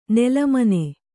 ♪ nela mane